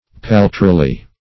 paltrily - definition of paltrily - synonyms, pronunciation, spelling from Free Dictionary Search Result for " paltrily" : The Collaborative International Dictionary of English v.0.48: paltrily \pal"tri*ly\ (p[add]l"tr[i^]*l[y^]), adv. In a paltry manner.